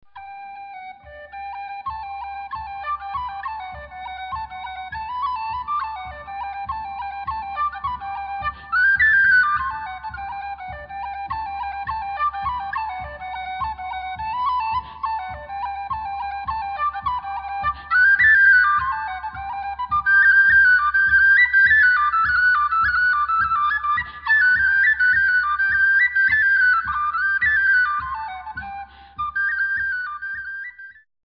whistle